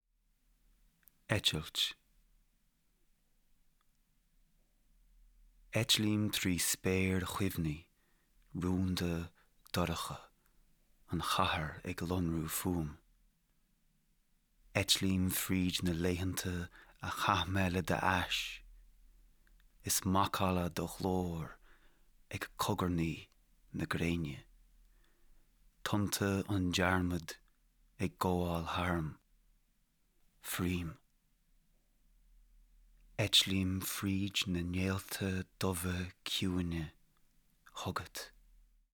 Spoken word actor
Spoken word recording